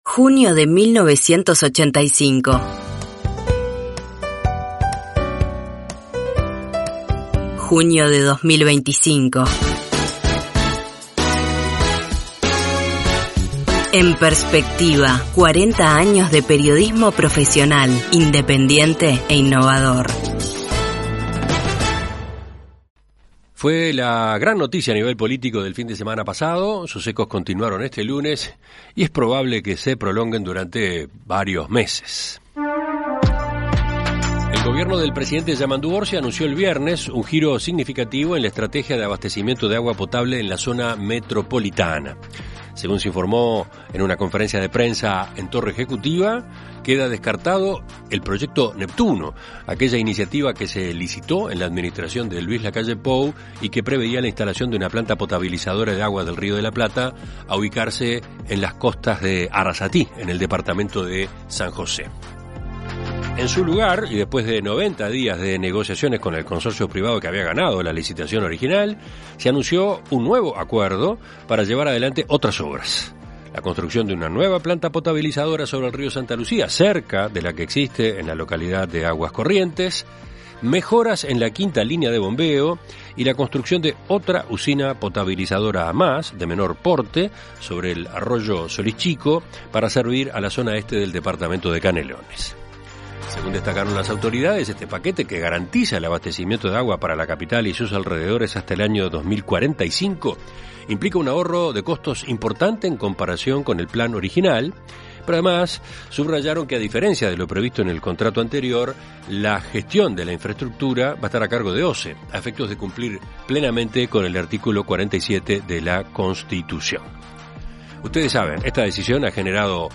En Perspectiva Zona 1 – Entrevista Central